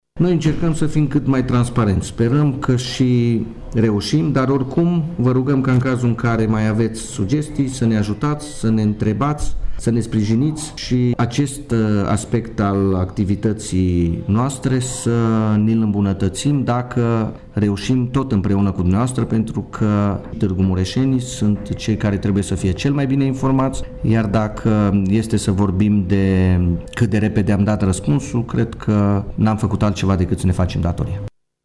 Viceprimarul municipiului, Claudiu Maior, e gata să îmbunătățească dialogul cu cetățenii: